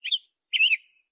Samsung Galaxy Bildirim Sesleri - Dijital Eşik
Chirps
chirps.mp3